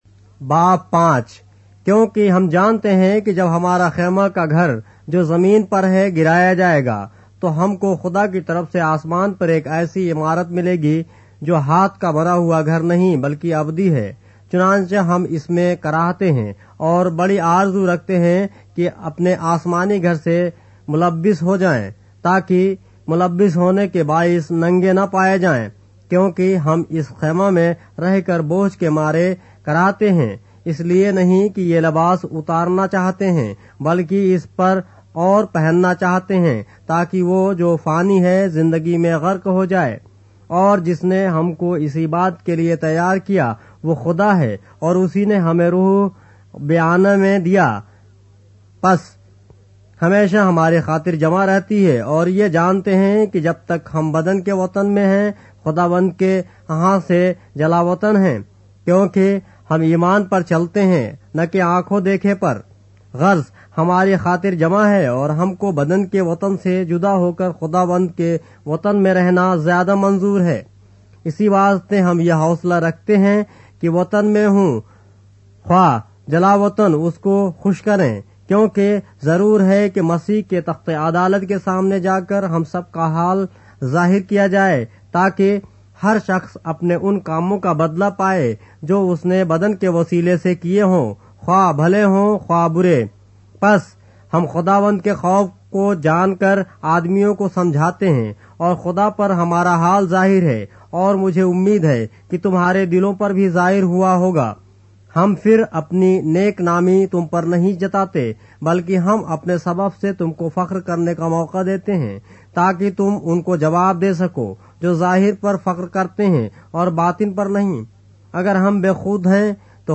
اردو بائبل کے باب - آڈیو روایت کے ساتھ - 2 Corinthians, chapter 5 of the Holy Bible in Urdu